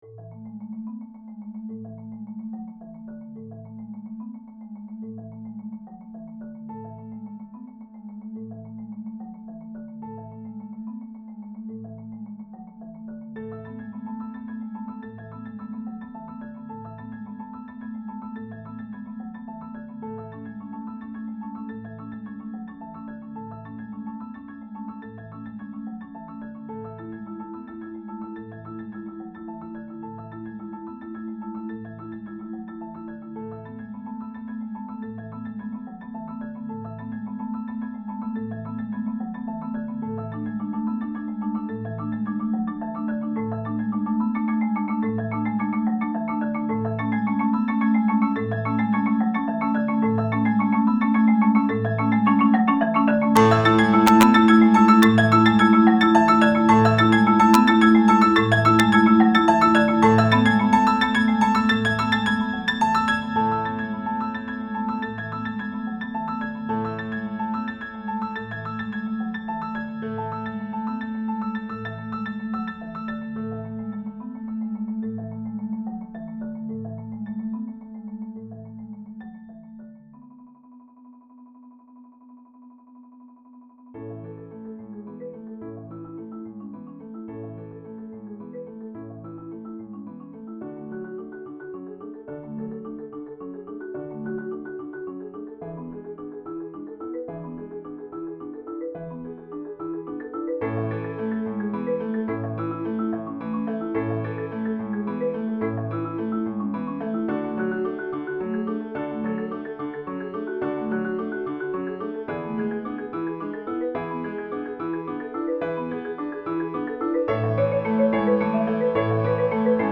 Genre: Duet for Marimba & Piano
Marimba (5-octave)
Piano